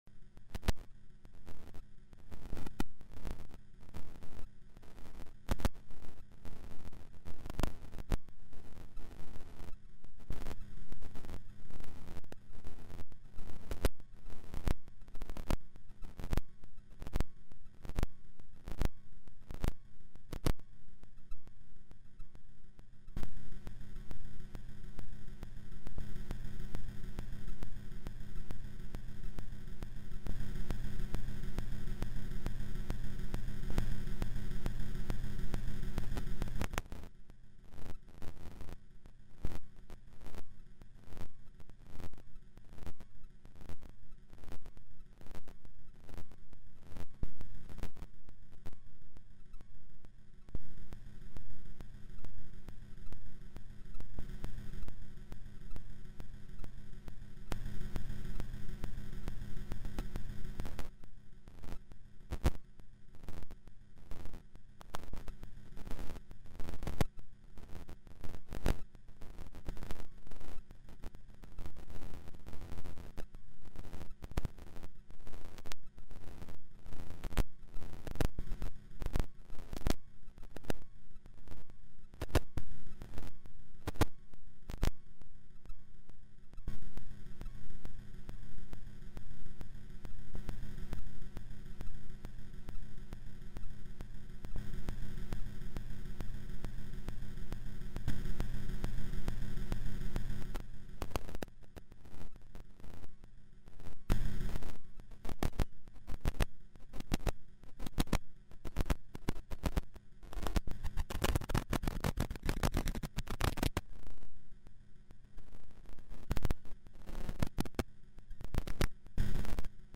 File under: Minimal / Microscopic Explorations